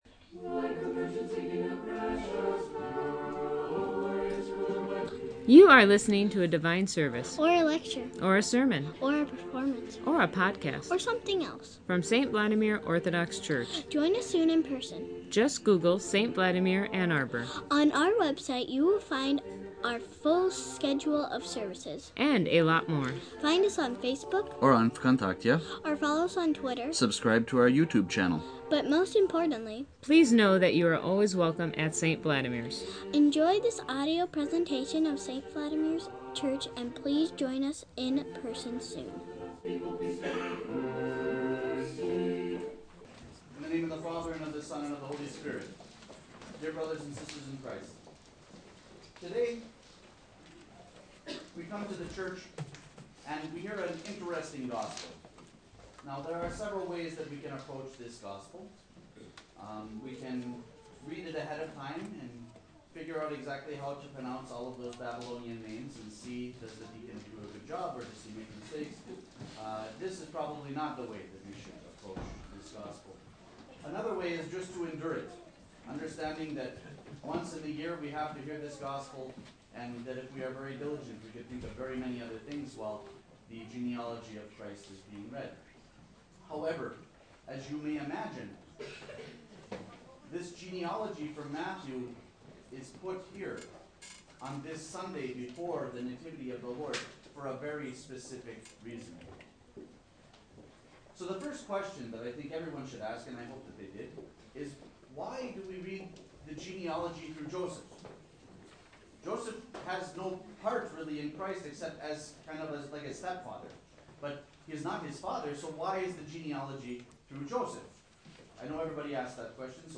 Please find the sound files below for the Sunday before Theophany at St. Vladimir's. This Sunday fell on the feast of the Synaxis of the 70 Apostles this year.